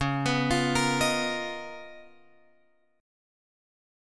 C# 13th